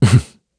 Siegfried-Vox-Laugh_kr.wav